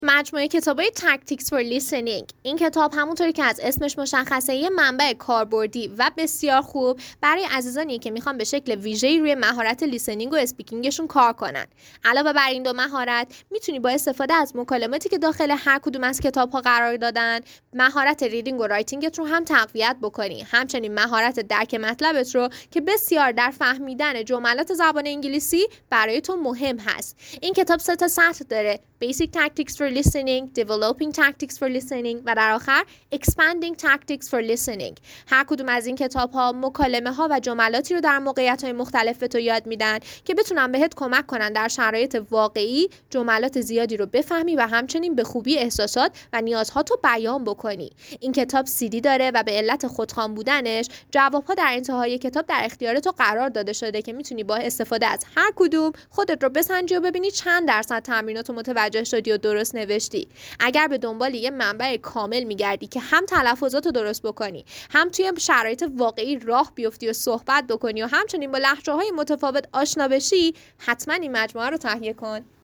پادکست معرفی دسته بندی کتاب Tactics For Listening